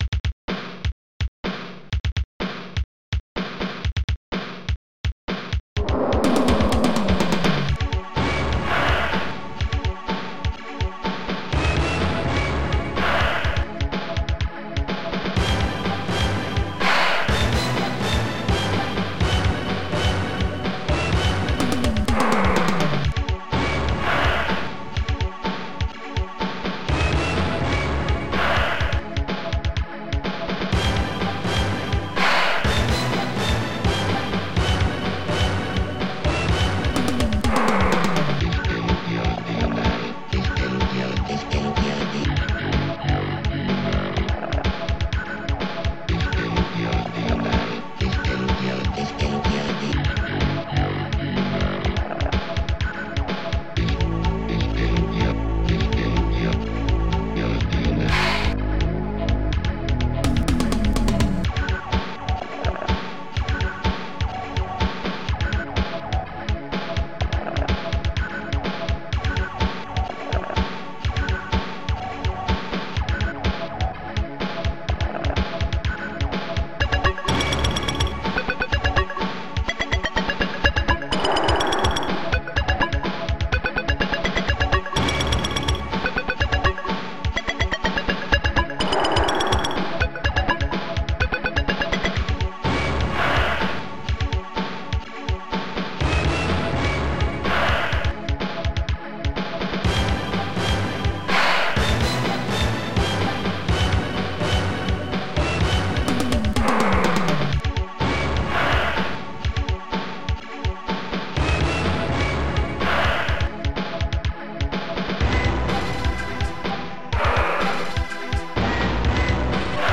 st-69:hbbassdrum4
st-69:hbsnare1
st-69:hightom
st-01:analogstring